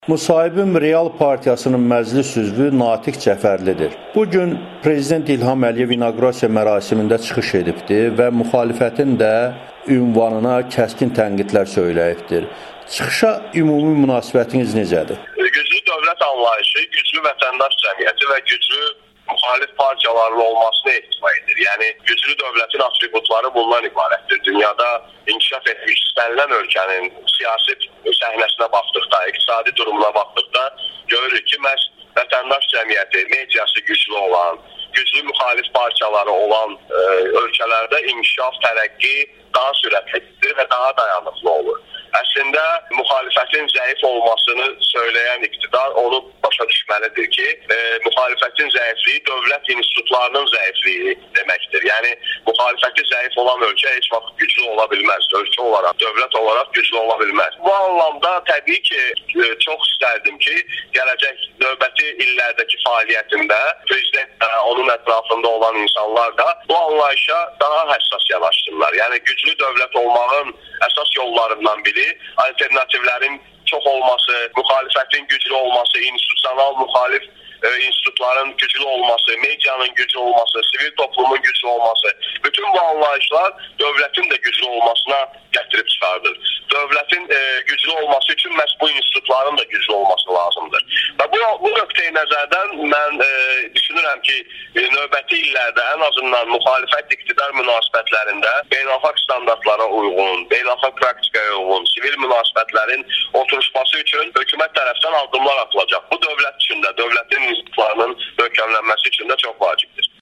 İlham Əliyevin andiçmə çıxışı - müxtəlif münasibətlər [Audio-müsahibələr]